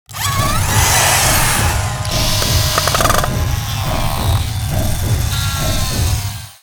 factoryRepair.wav